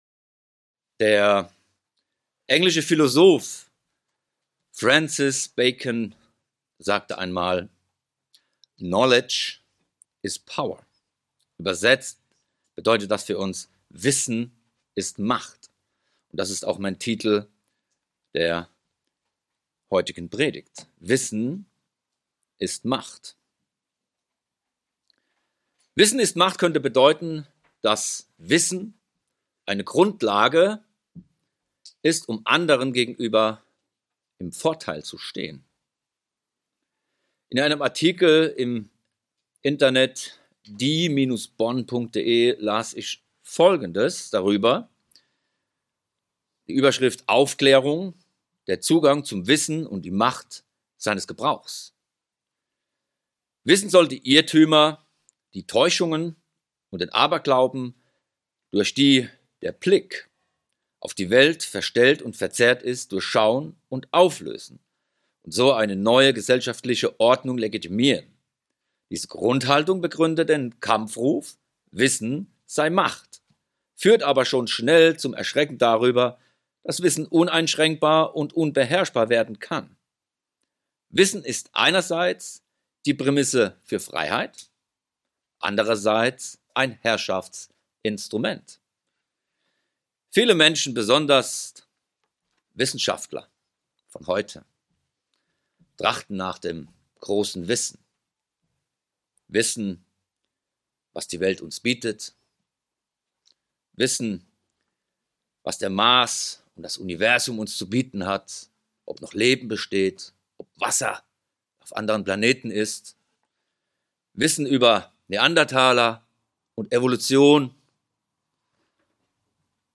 Diese Predigt erklärt, wann Wissen echte Macht ist!